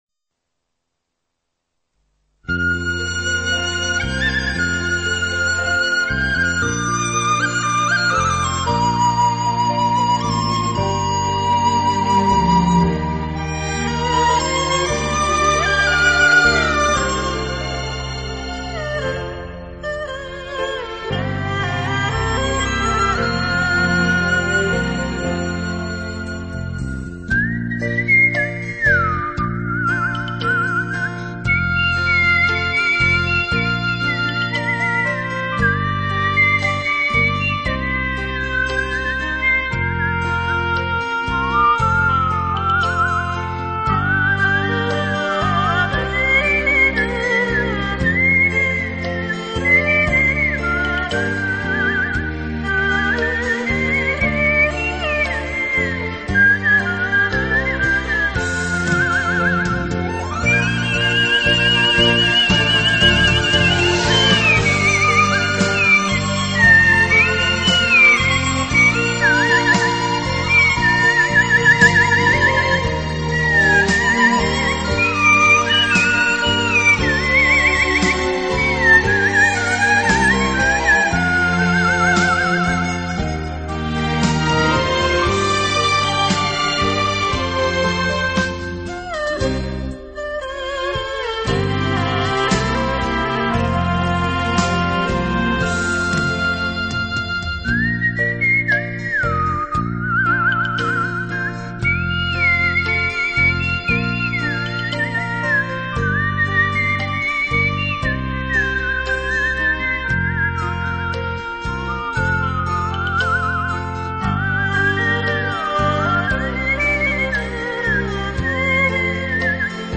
最动听的口哨音乐
最朴素的声音带来了一股清凉的风
透明、神秘的口哨声充满了朦胧的氛围